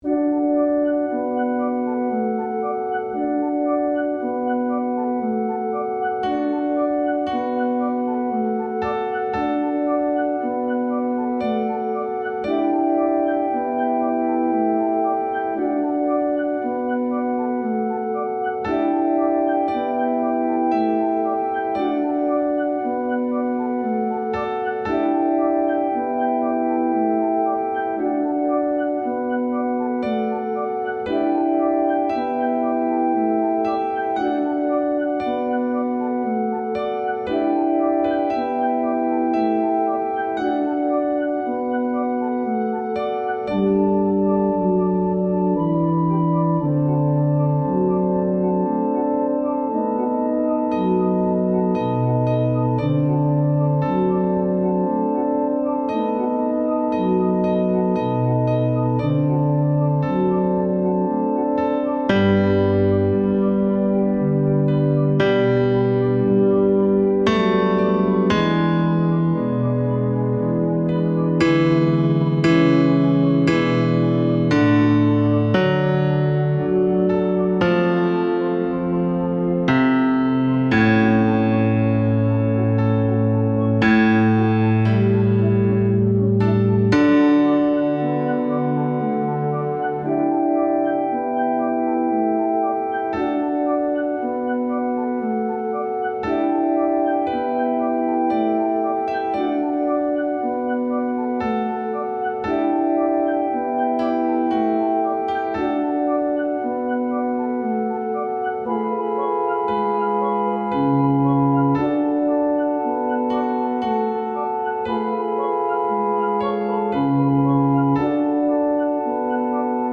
Bajo I
Bajo-I-In-PAradisum-MUSICA-Mp3-1.mp3